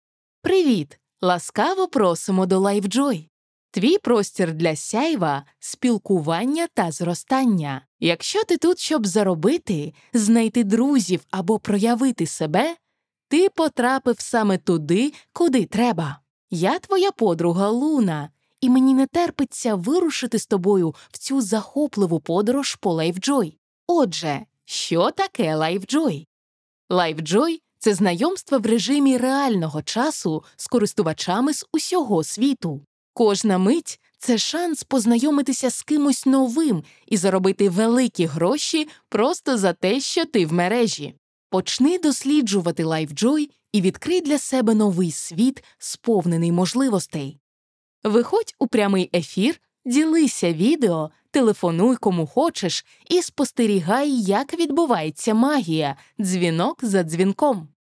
Diep, Natuurlijk, Veelzijdig